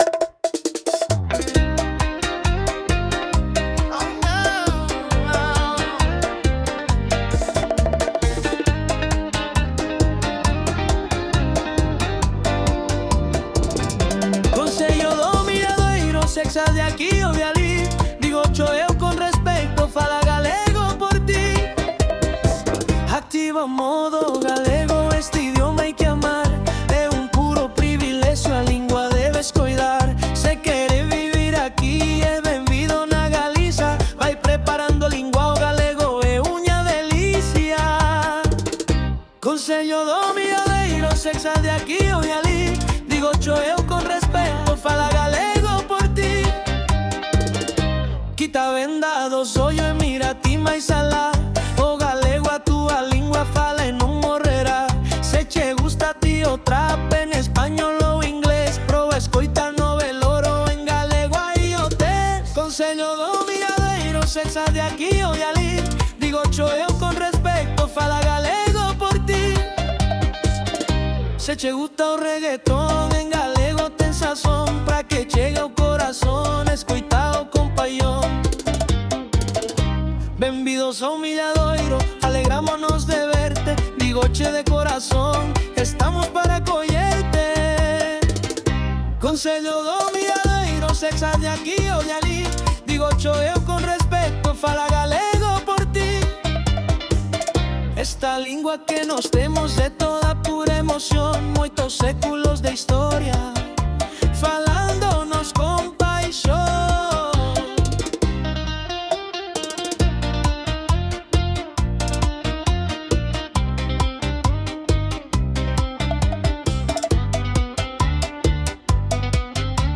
Queremos presentarvos algo moi chulo que fixo o alumnado de música de 4º de ESO do IES do Milladoiro.
Mandaron dúas versións da canción.
Versión bachata:
Bachata.m4a